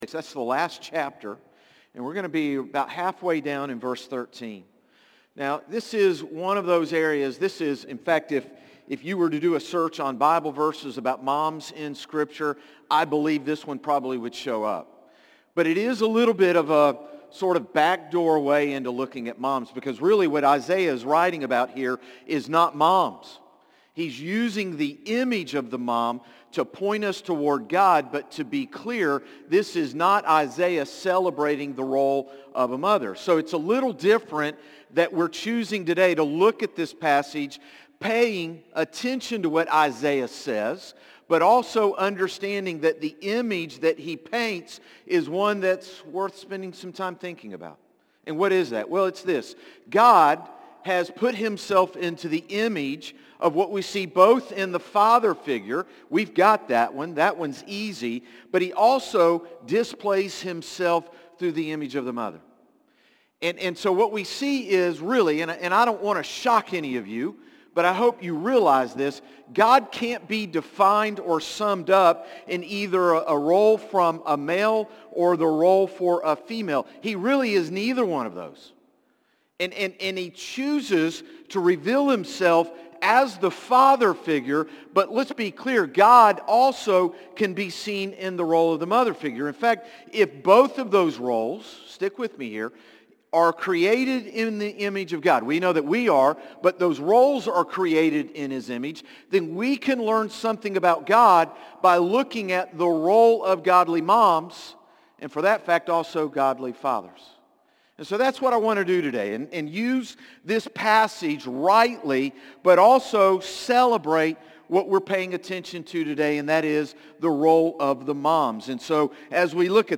Sermons - Concord Baptist Church
Morning-Service-5-11-25.mp3